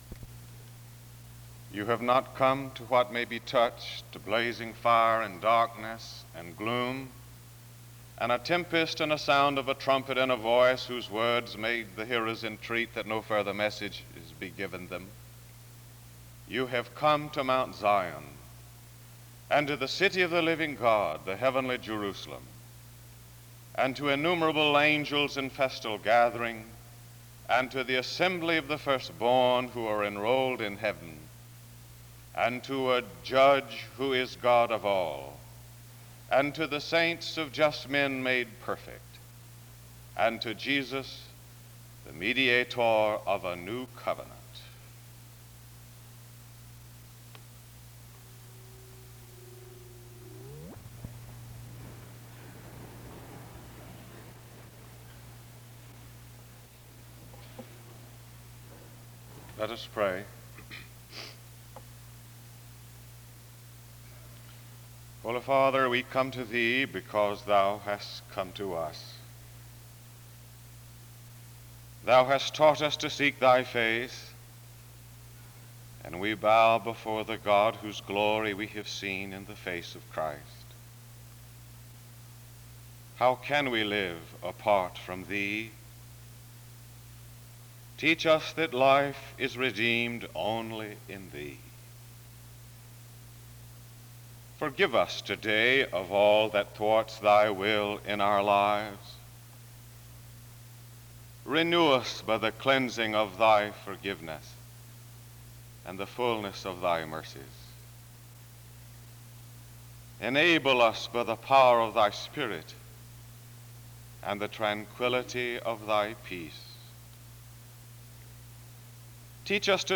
The service opens with a reading of a portion of scripture from 0:00-00:45. A prayer is offered from 0:58-3:10.
Music plays from 3:10-8:42.